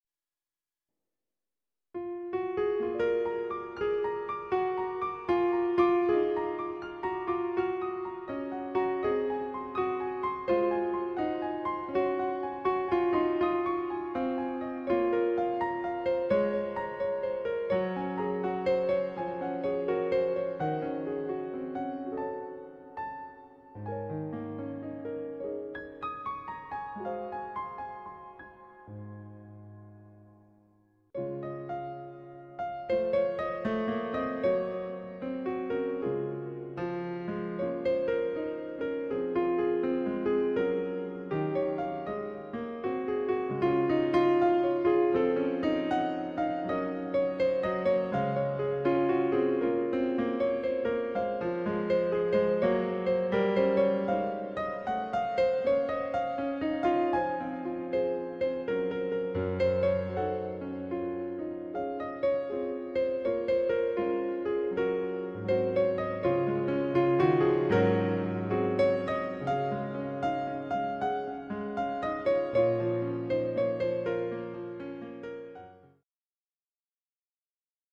J-pop 弾いてみた編
「今は・・・」の歌詞が3連符のリズムに乗っていきなりのクライマックスです。